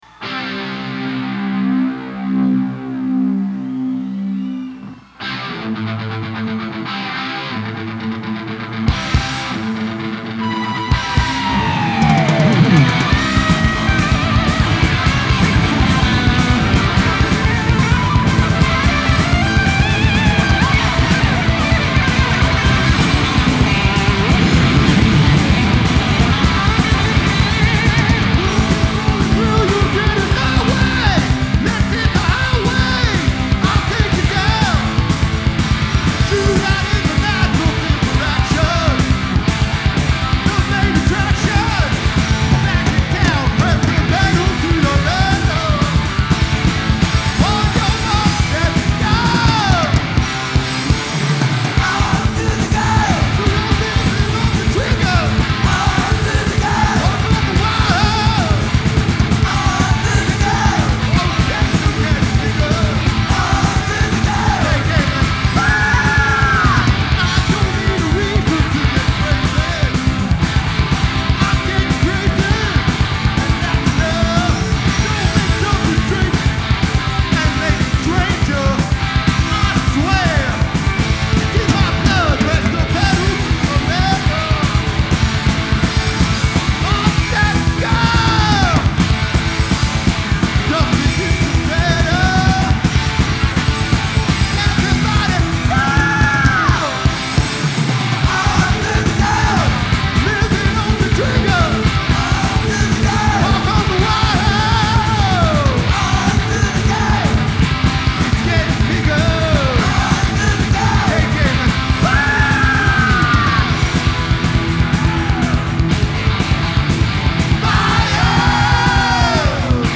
Genre : Rock
Live From Mid-Hudson Civic Arena, Poughkeepsie NY